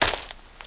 STEPS Leaves, Walk 17.wav